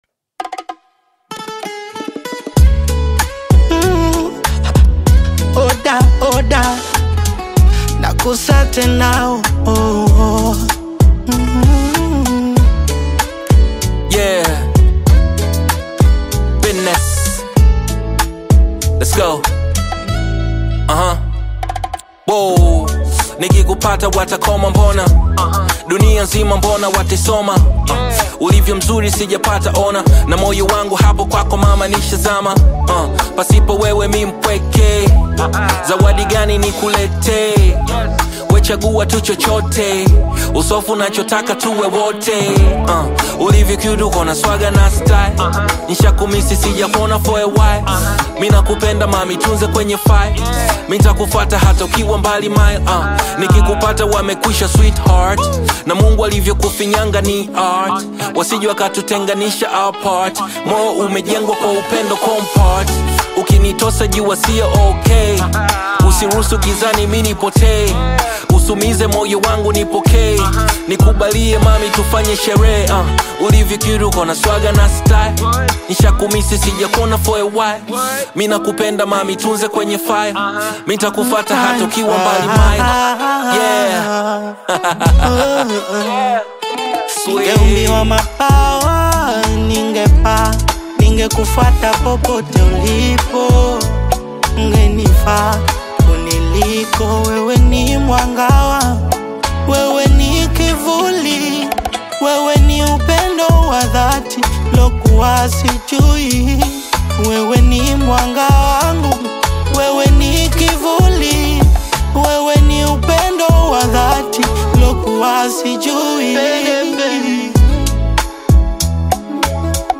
soulful singer